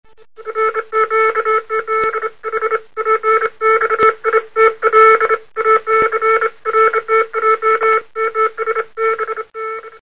Сигнал SOS [40кб]